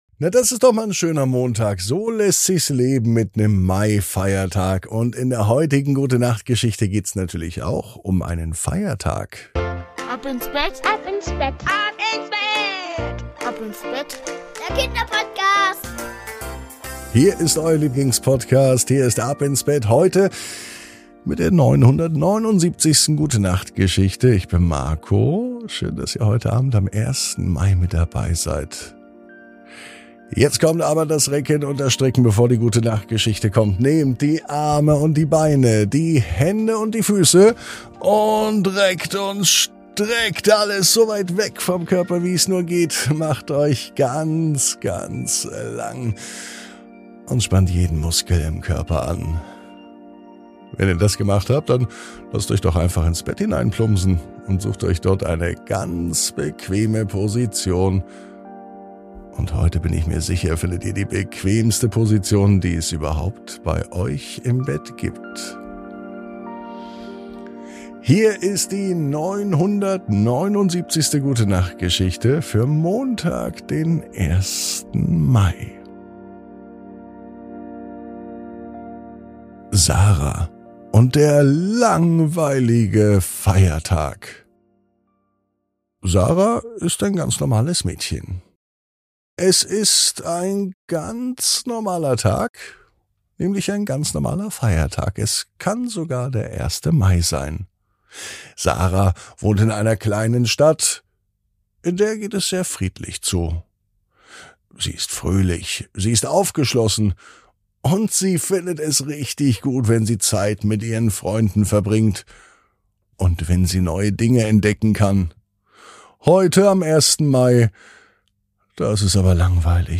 Die Gute Nacht Geschichte für den Feiertag